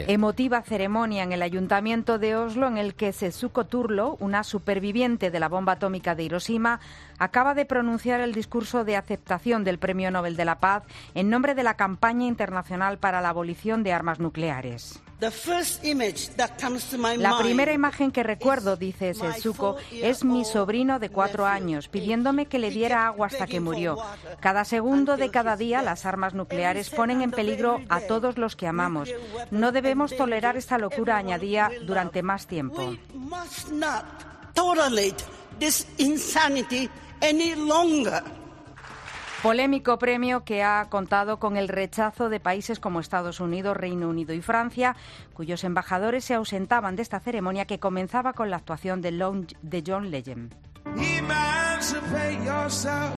La ICAN recoge el Premio Nobel de Paz. Crónica